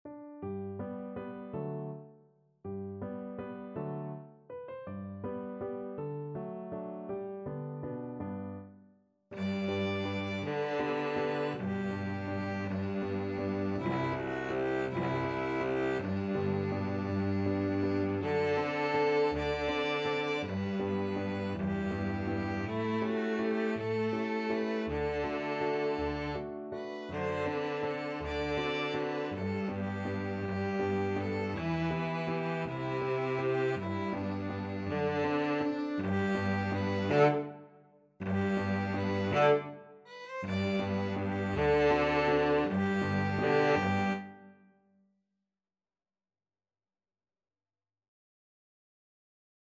ViolinCelloPiano
3/4 (View more 3/4 Music)
One in a bar . = c.54
Pop (View more Pop Piano Trio Music)